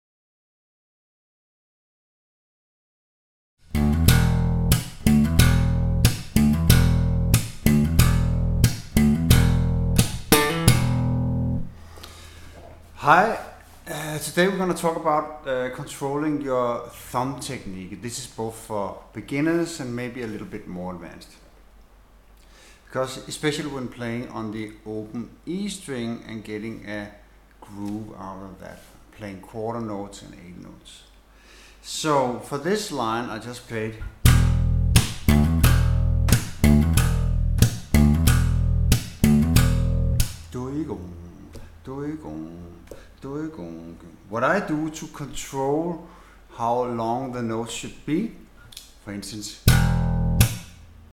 01 Slap Bass 101 for novice slappers
Using slapping techniques made popular by Larry Graham, Louis Johnson, Victor Wooten, Marcus Miller among others